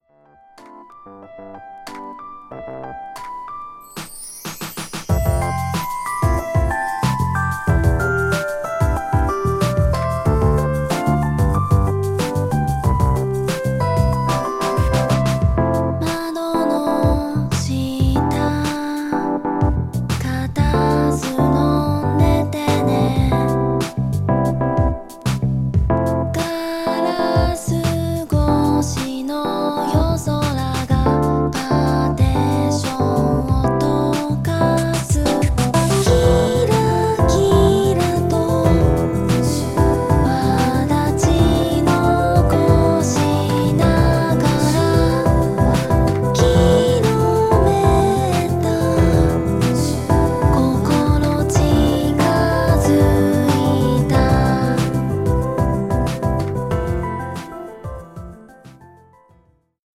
メタポップ